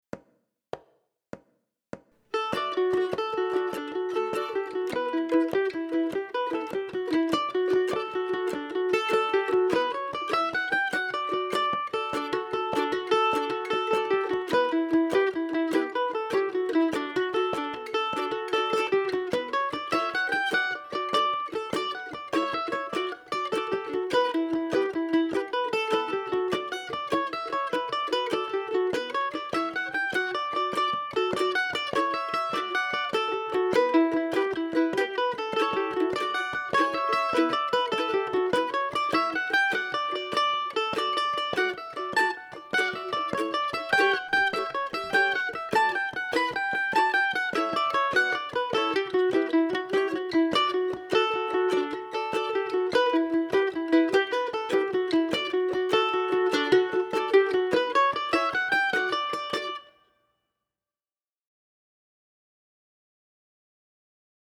MANDOLIN SOLO Celtic/Irish, Mandolin Solo
DIGITAL SHEET MUSIC - MANDOLIN SOLO